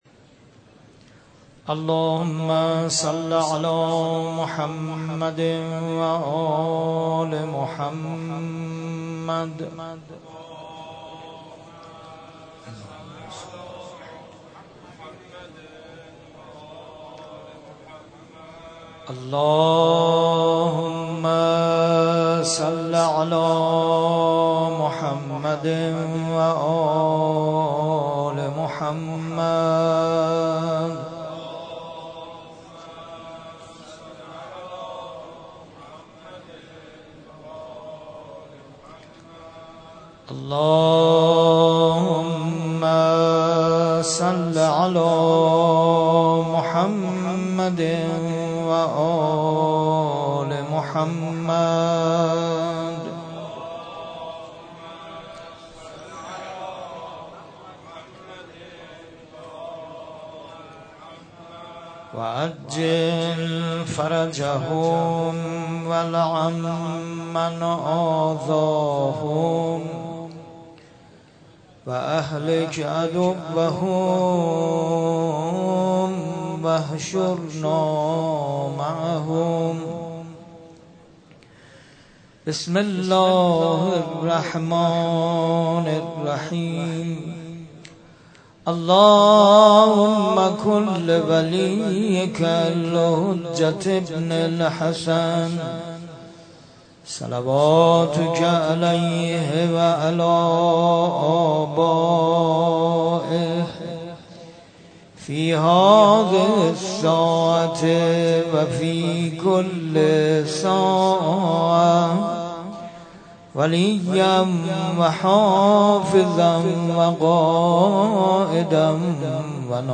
قرائت دعای ابوحمزه (قسمت سوم) ، روضه حضرت زینب (علیها السلام)